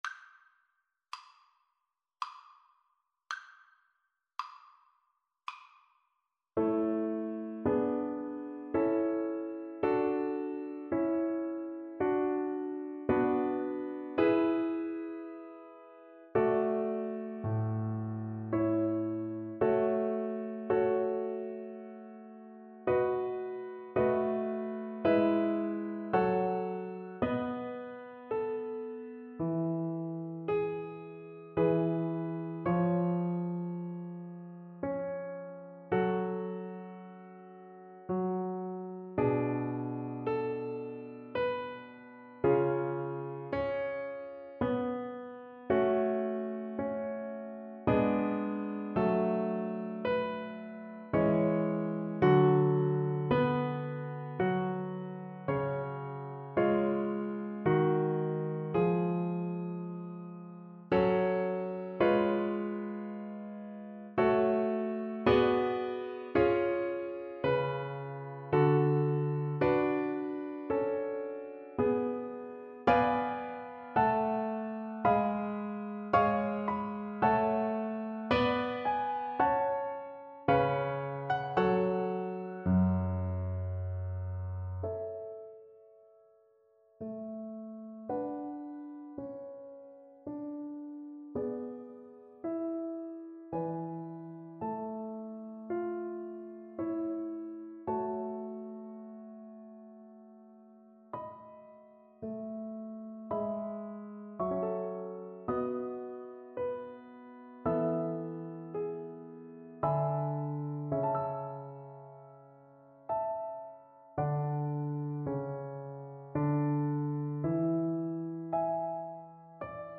Andantino =80 (View more music marked Andantino)
3/4 (View more 3/4 Music)
Classical (View more Classical Viola Music)